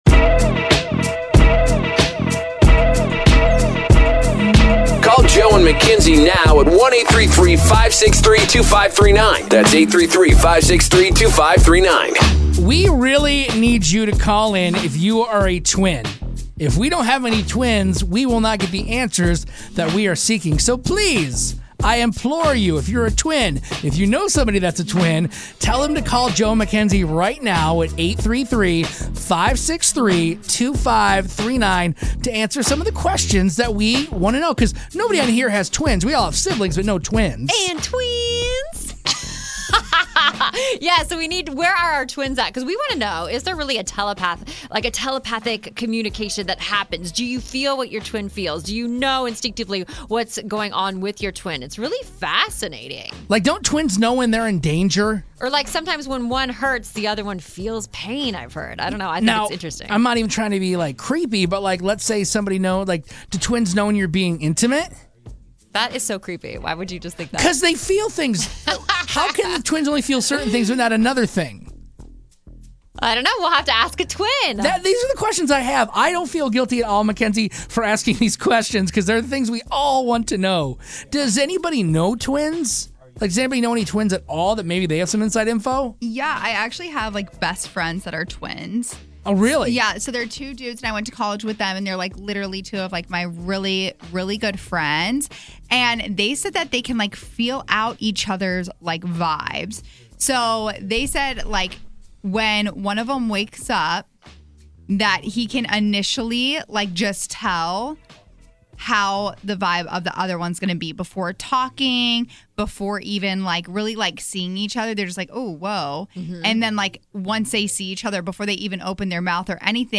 We have twins on air to see if they really do have special communicating powers!